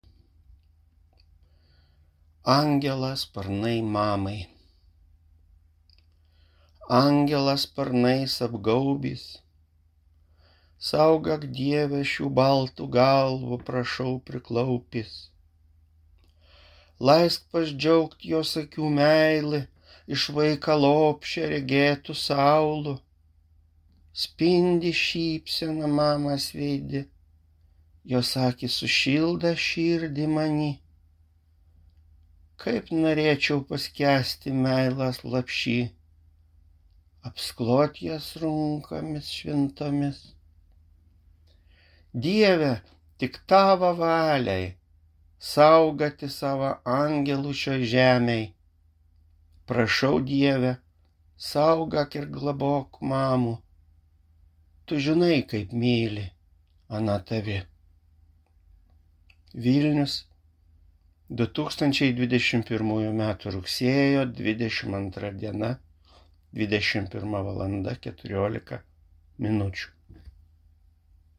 Įgarsinimai lietuvių kalba
Aukštaitiška tarmė
aukstaitiska-tarme-esu.mp3